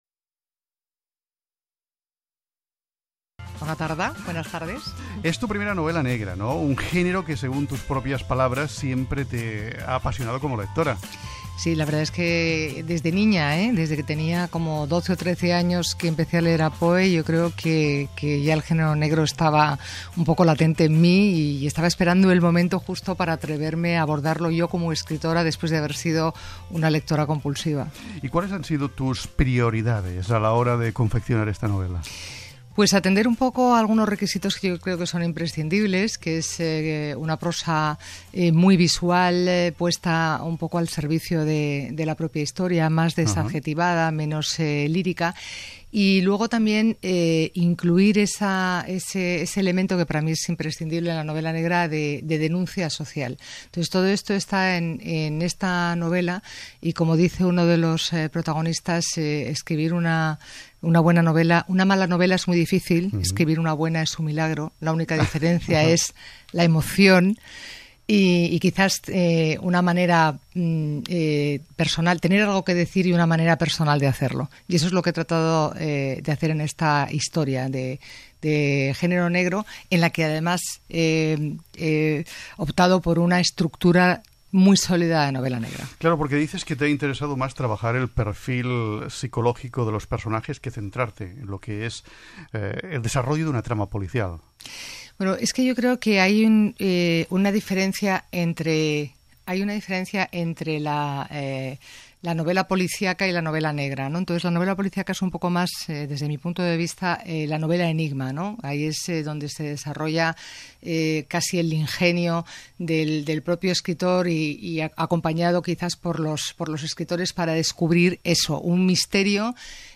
Entrevista a Marta Robles en Metròpoli Ràdio 4